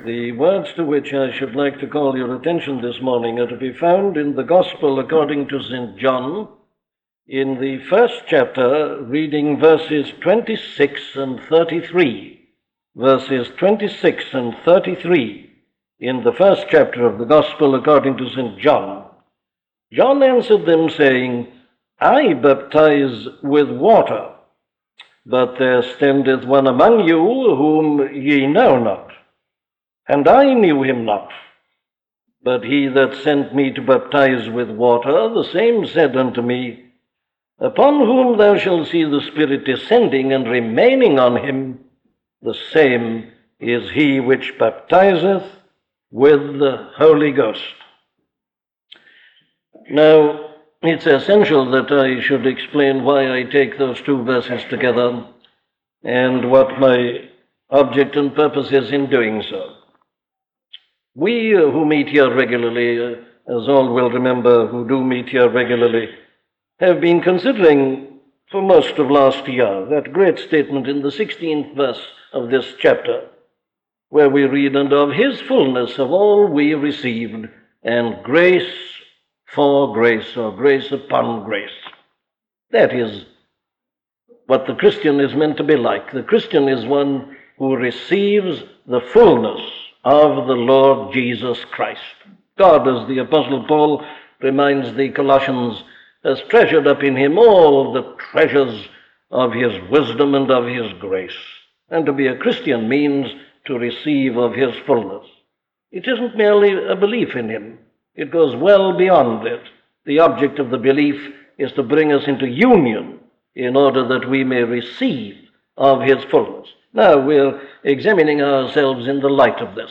Martyn Lloyd-Jones sermons | Book of John series | Chapter 1
An audio library of the sermons of Dr. Martyn Lloyd-Jones.